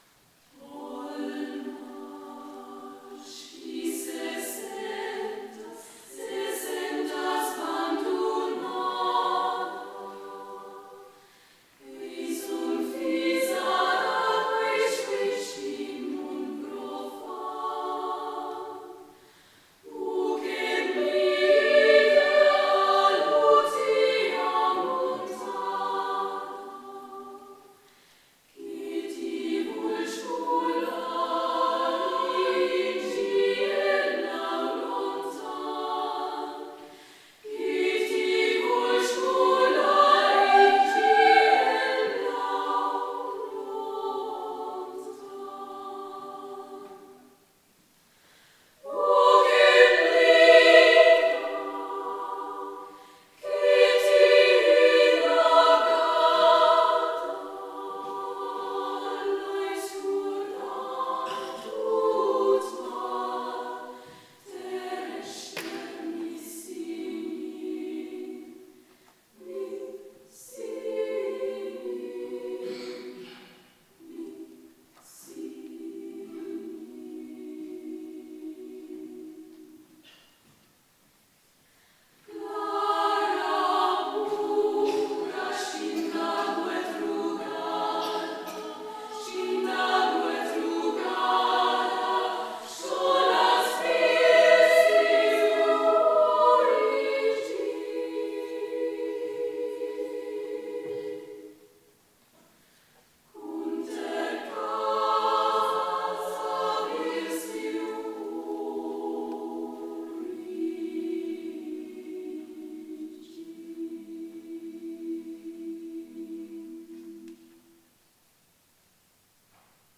Genre-Style-Forme : Profane ; Chanson
Caractère de la pièce : calme
Type de choeur : SSAA  (4 voix égales de femmes )
Tonalité : sol majeur ; mi majeur